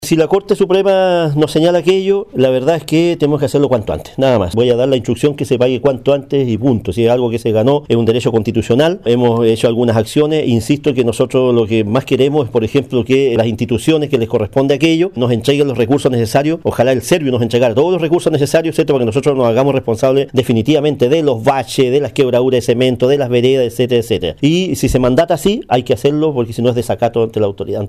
El alcalde Gervoy Paredes, señalo que como municipio en su momento interpusieron un recurso en la Corte, sin embargo en fallo unánime los magistrados de la Tercera Sala de la Corte Suprema, rechazaron el recurso de casación interpuesto por la municipalidad.